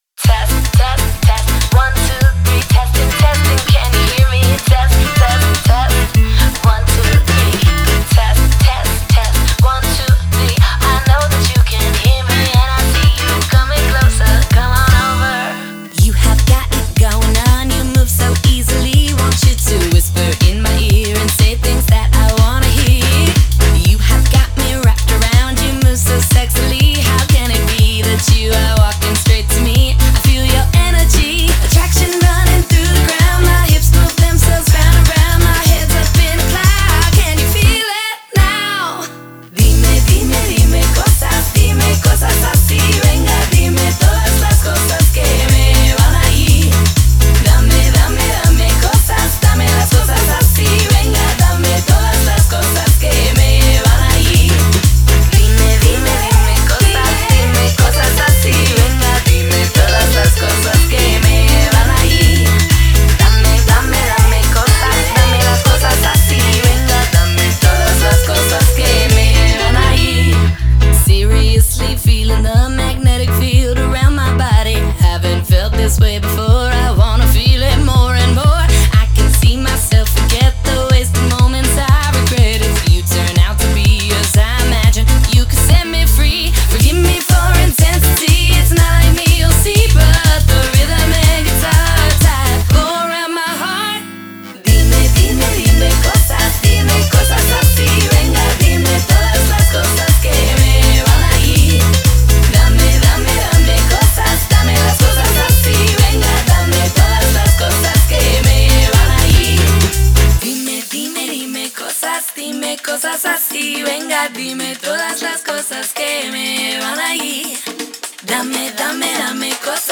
Original English/Spanish Electro Swing